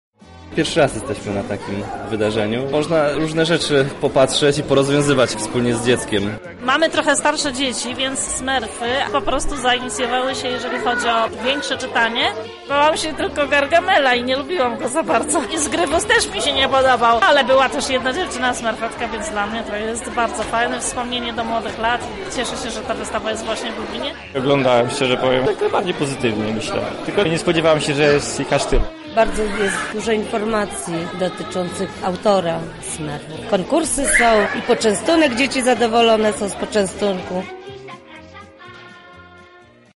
Na miejscu była nasza reporterka